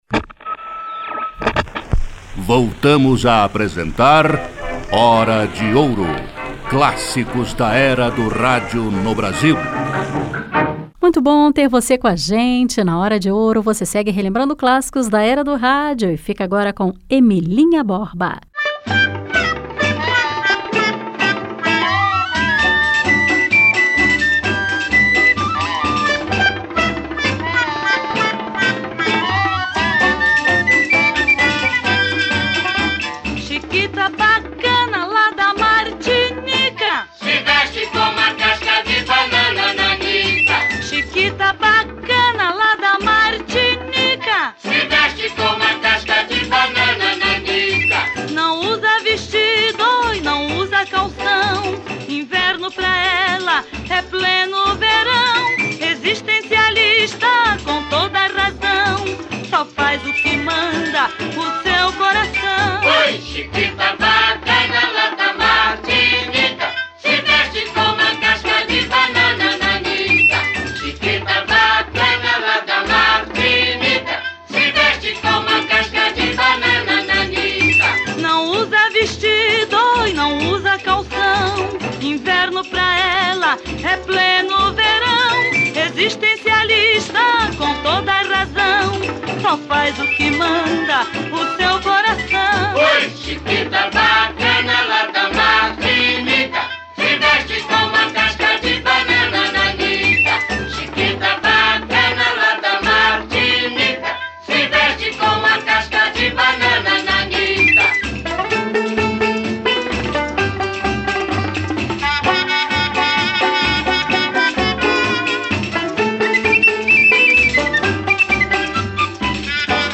choro